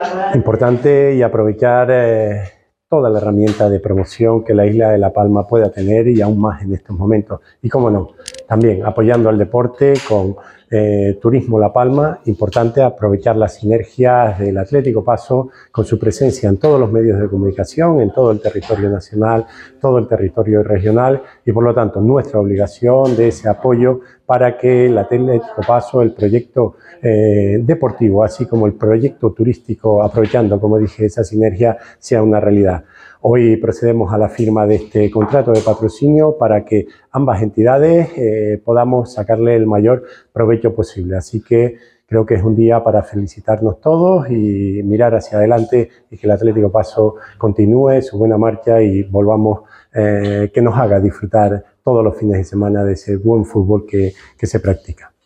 Declaraciones audio Raúl Camacho Atlético Paso.mp3